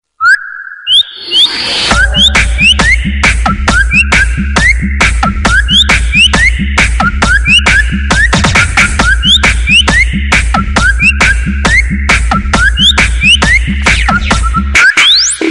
Главная » Рингтоны » Рингтоны звуки животных